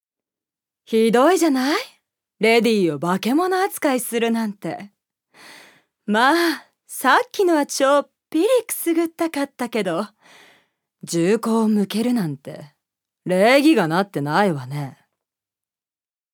預かり：女性
セリフ２